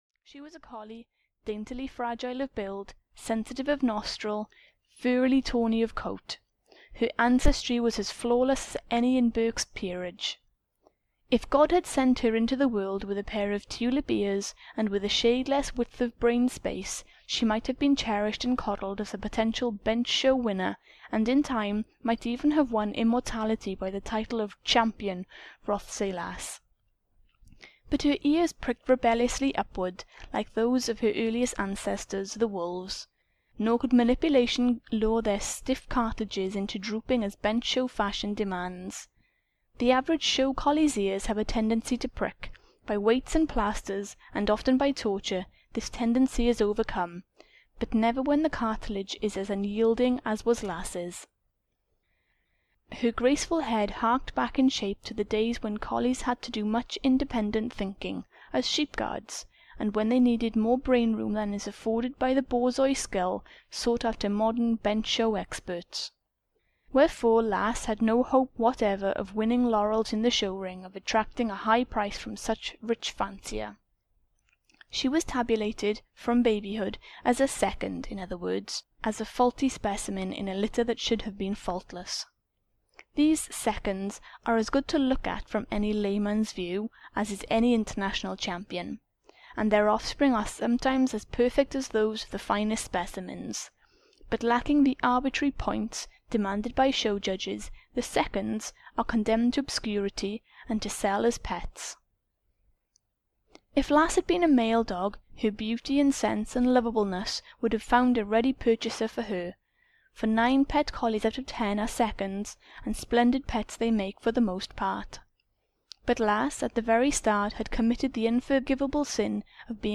Bruce (EN) audiokniha
Ukázka z knihy